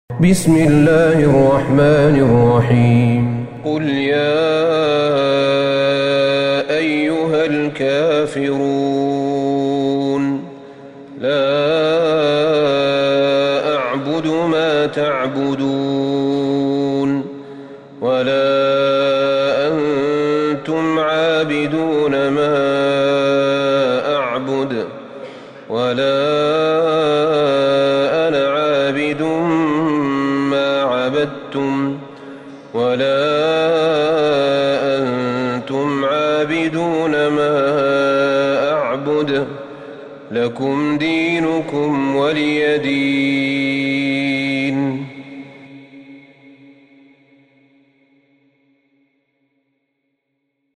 سورة الكافرون Surat Al-Kafirun > مصحف الشيخ أحمد بن طالب بن حميد من الحرم النبوي > المصحف - تلاوات الحرمين